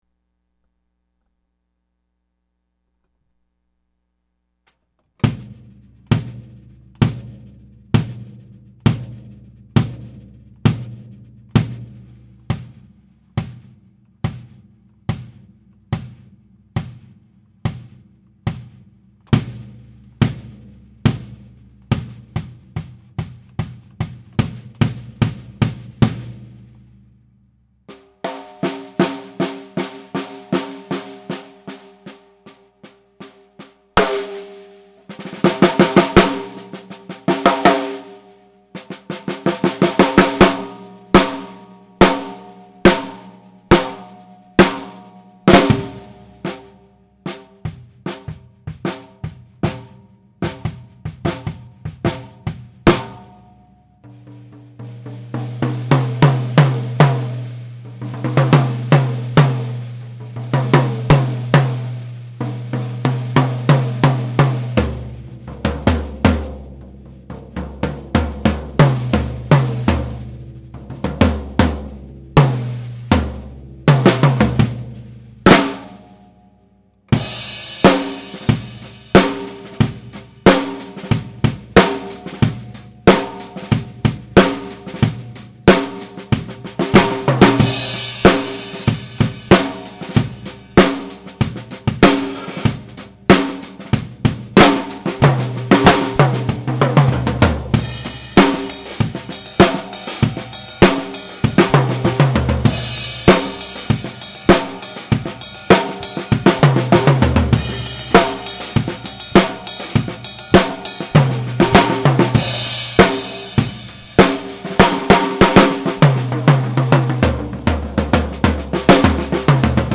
Jenkins-Martin fiberglass drum sound files - Page 1 - Vintage Drum Forum
"22 bass drum with Aquarian Super Kick 1 and the Evans pillow you had in the drum. Front head is a ported Ambassador
12 & 16 toms have clear Ambassador bottom, coated Ambassador top and zero muffling
Drums are recorded on an M-Audio Microtrack-II with a Tascam stereo mic just over the kit at head level. No EQ, compression, etc. and no other mics.
The room is small and padded down so there is little ambient coloring. It is not the most complimentary sounding drum room."
Low tunings
Low tuning.mp3